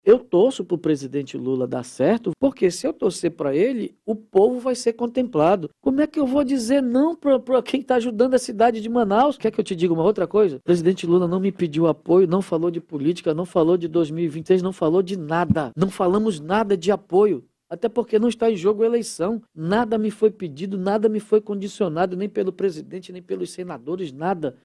Em entrevista ao programa